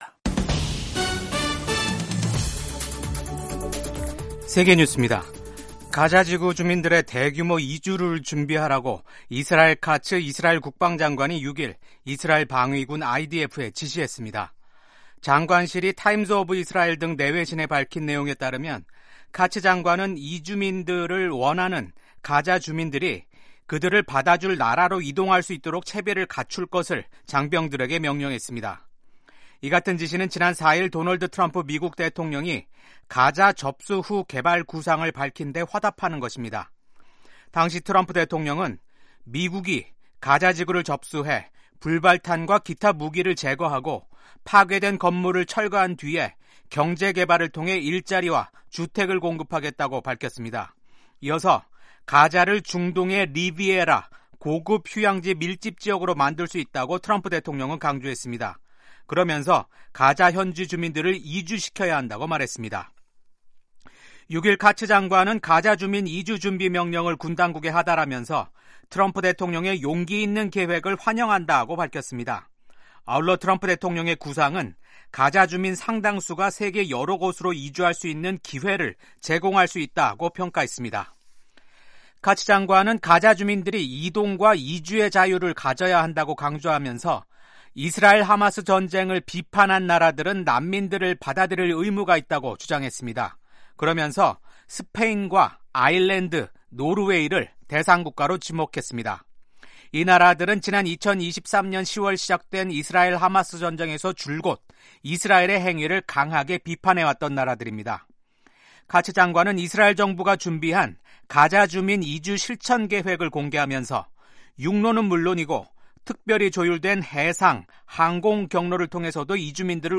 생방송 여기는 워싱턴입니다 2025/2/7 아침